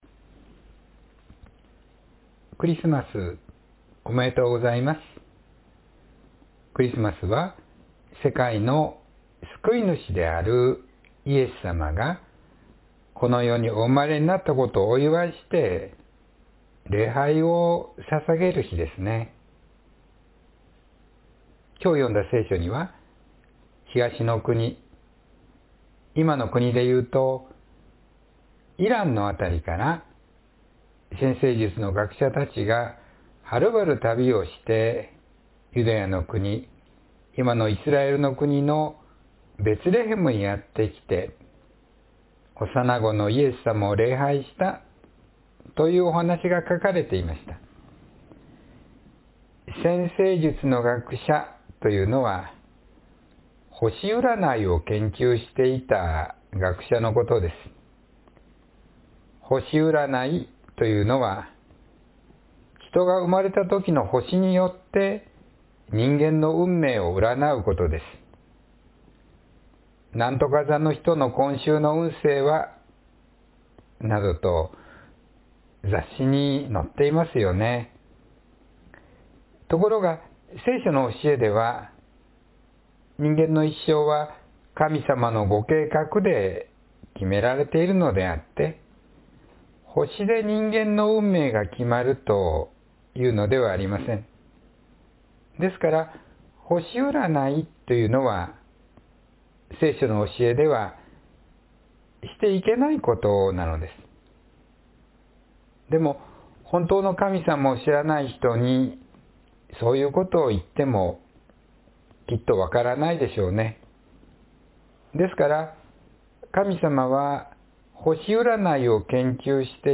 イエスさまを礼拝する（2025年12月21日・子ども説教）